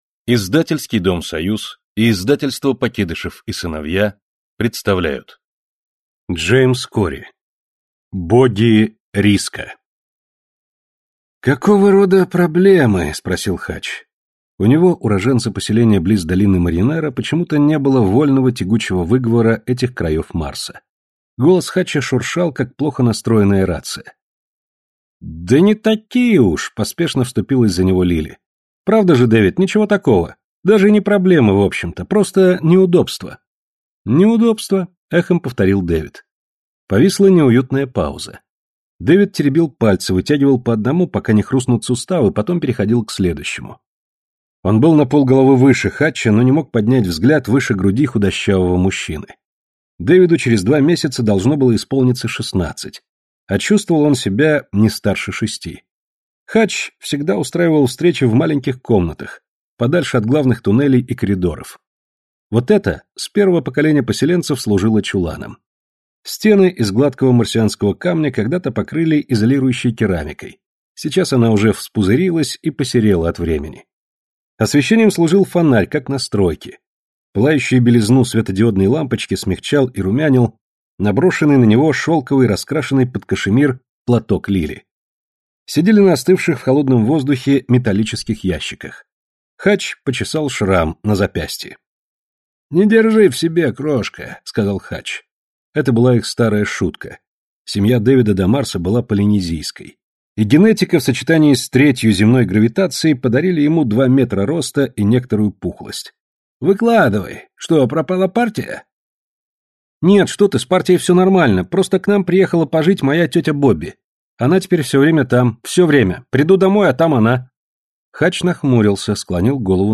Аудиокнига Грехи отцов наших и другие рассказы | Библиотека аудиокниг